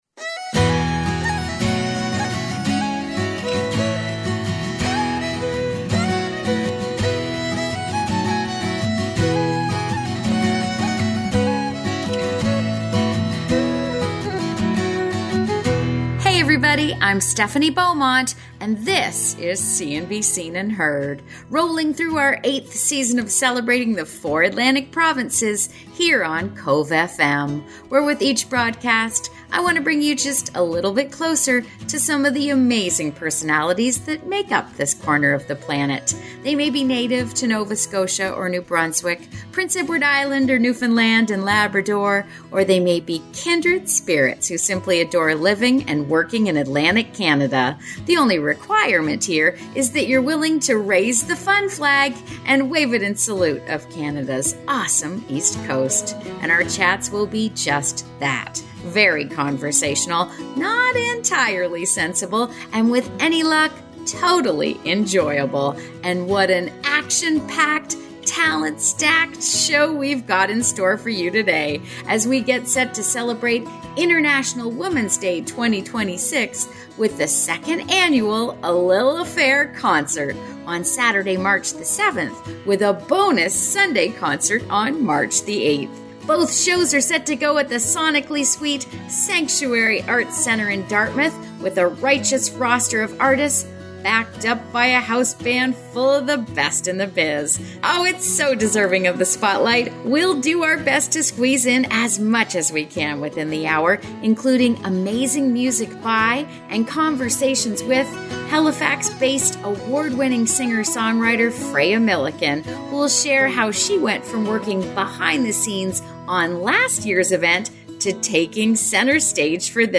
Our chats will be just that, very conversational, not entirely sensible and with any luck, totally enjoyable. And what an action packed, talent stacked show we’ve got in store for your today as we get set to celebrate INTERNATIONAL WOMEN’S DAY 2026 with the 2nd Annual A LI’L AFFAIR Concert on Saturday, March 7th with a bonus Sunday concert on March 8th – both shows are set to go at the sonically sweet Sanctuary Arts Centre in Dartmouth with a righteous roster of artists backed up by a house band full of the best in the biz.